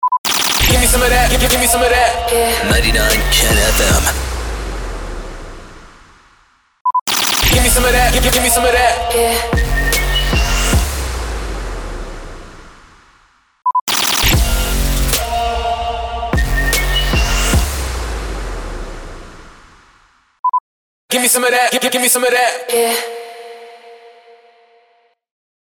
208 – SWEEPER – GIMME SOME OF THAT
208-SWEEPER-GIMME-SOME-OF-THAT.mp3